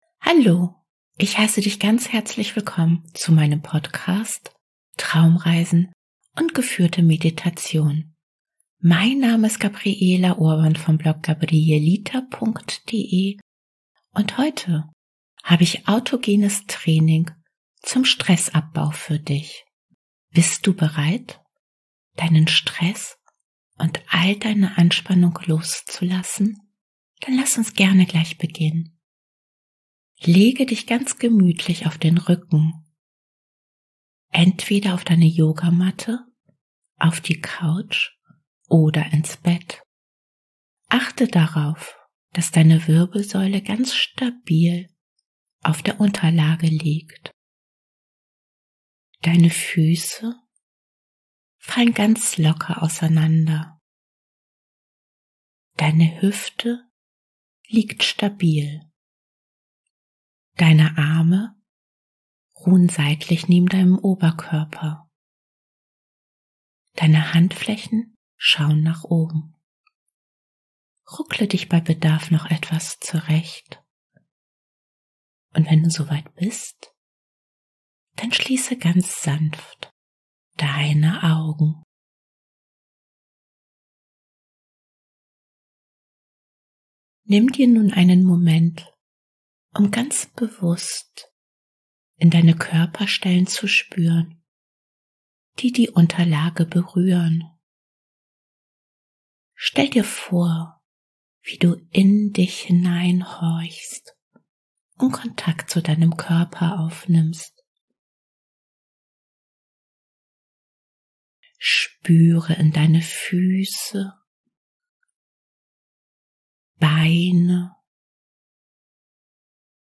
Autogenes Training ohne Musik, mit dem du all deinen Stress loslassen kannst.